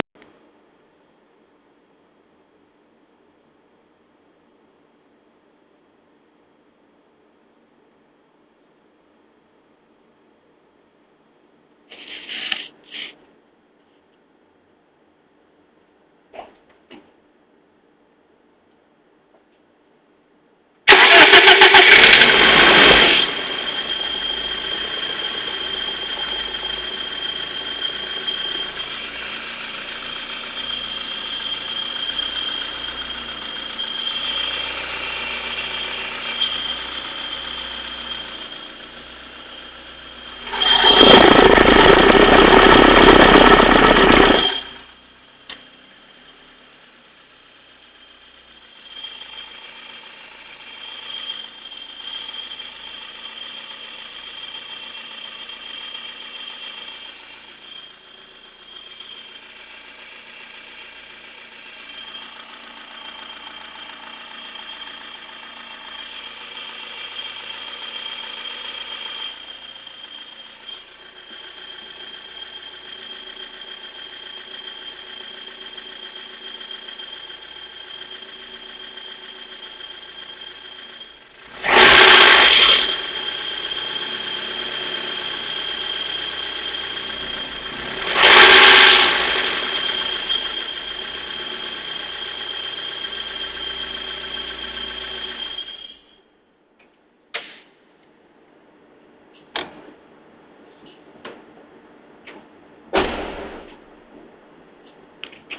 J'aimerai avoir votre avis sur le bruit de ce moteur M40B16 dans une E30, froide :
A la seconde 21, c'est le démarrage du moteur : je pense que ça, ça va, mais peut-être allez vous me dire qu'il y a un soucis ?
De la seconde 23 à la seconde 39, c'est le bruit du moteur de l'extérieur, avec le micro dans le compartiment moteur;
De la seconde 40 à 44, c'est le bruit du moteur en placant le micro à la place du bouchon de remplissage d'huile, bouchon enlevé.
Le reste, c'est le moteur depuis le compartiment avec à la fin deux-trois accélérations très douces et légères.
Moi j'entends : un soufle permanent, ou une aspiration, je ne sais pas ce que c'est.
Bon je me rends compte en réecoutant que la bande son est vraiment pourrie.
engine.wav